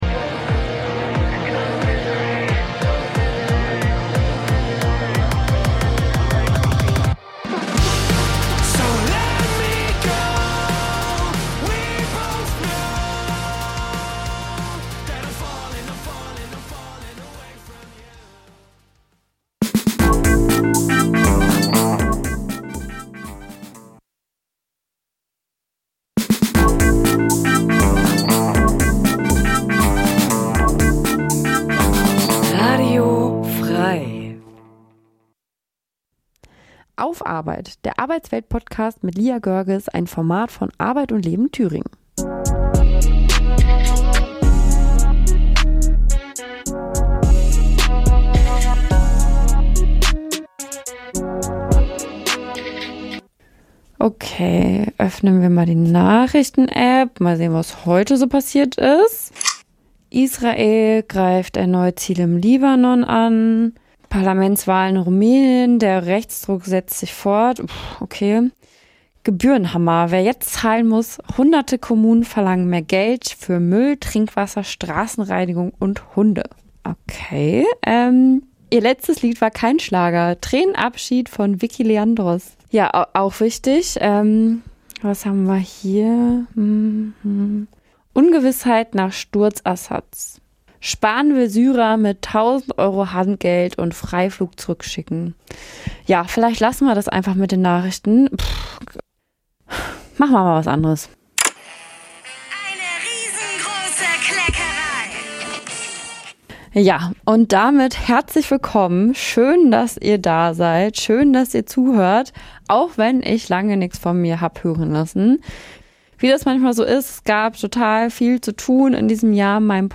Expterten zu diesen Themen befragen.